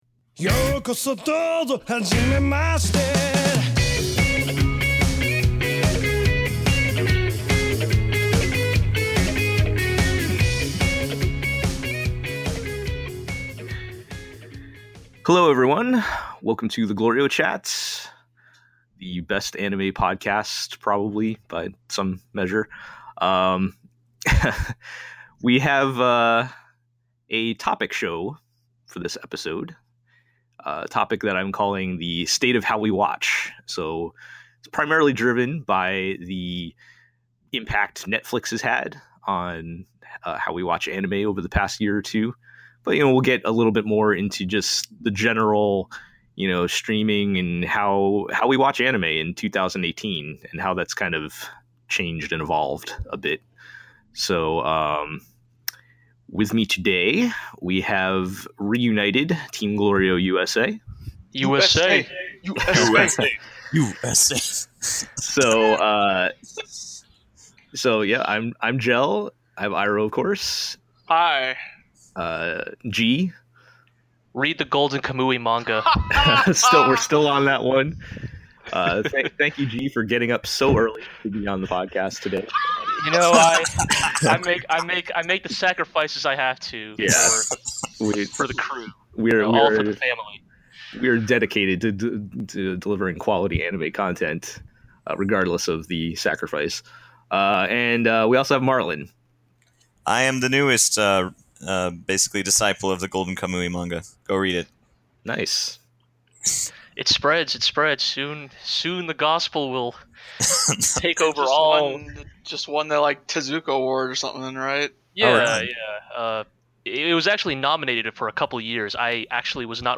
We might not be The Avengers, but nevertheless Team GLORIO USA has assembled for an honest discussion about Netflix and the state of how we watch anime in 2018.